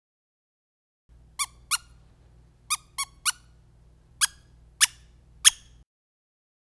Squeaker Pillow Single-Voice - Small (12 Pack) - Trick
Single-voice squeakers make a sound only when they are pressed, not when they are released.